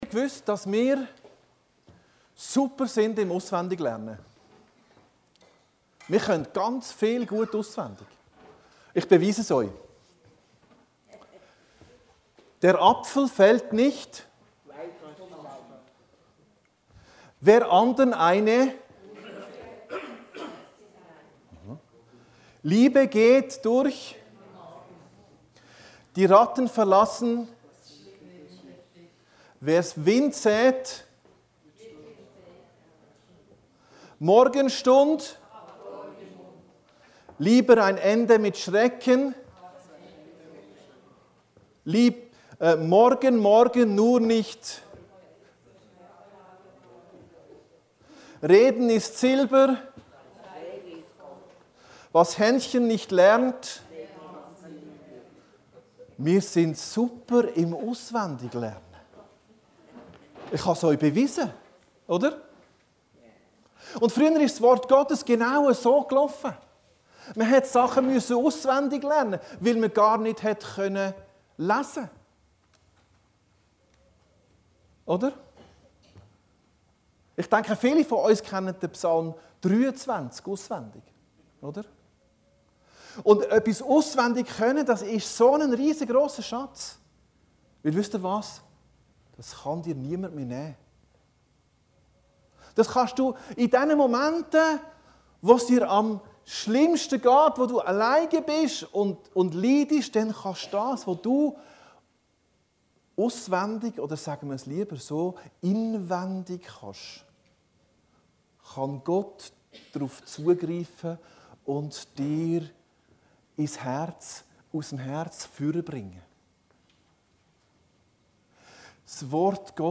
Predigten Heilsarmee Aargau Süd – Das Wort Gottes Psalm 119